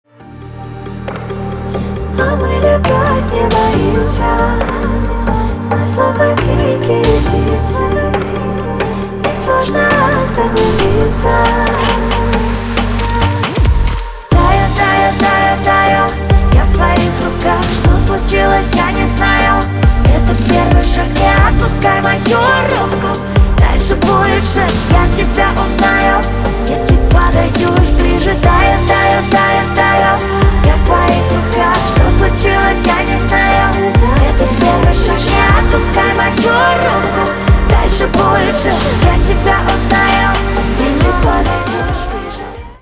• Качество: 320, Stereo
поп
медленные
нежные